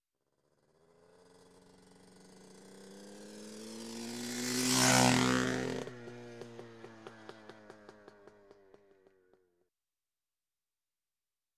Звук мотоцикла Эндуро
• Категория: Мотоциклы и мопеды
• Качество: Высокое